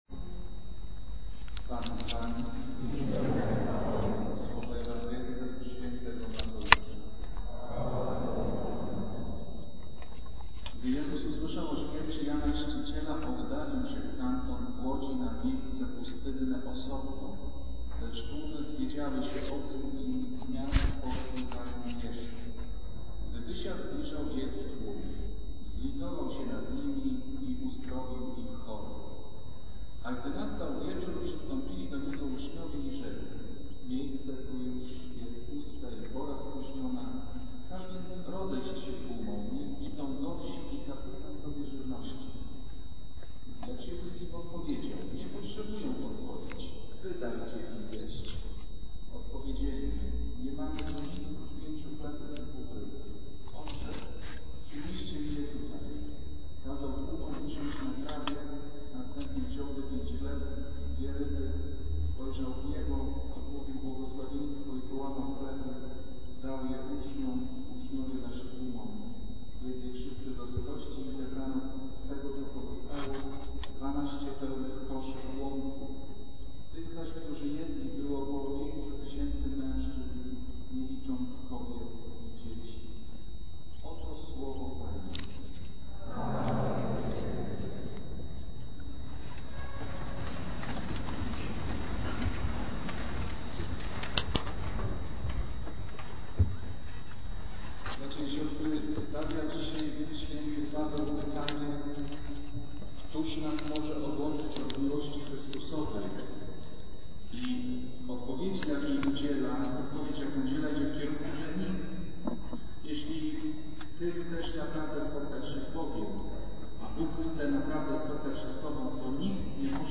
Kazanie z 23 sierpnia 2009r.